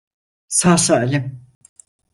Uitgespreek as (IPA) [sɑː saːlim]